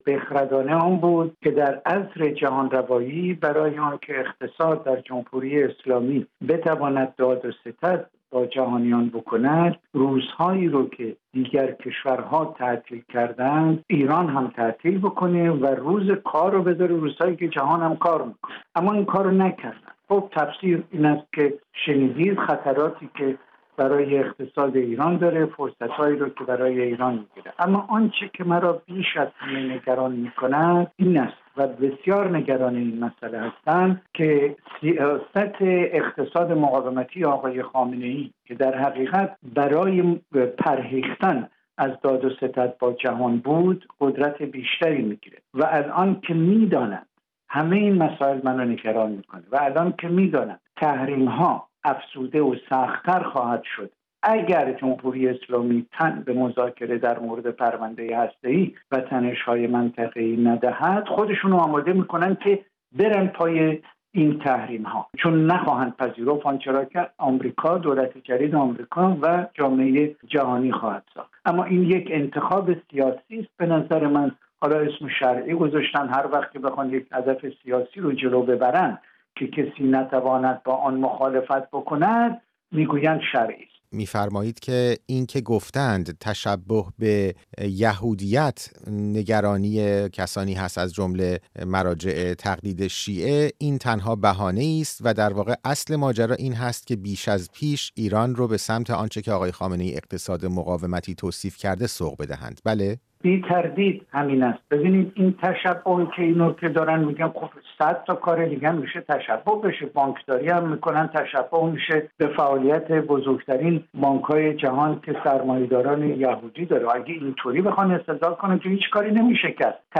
چرا تعطیلی پنجشنبه به جای شنبه؟ گفت‌وگو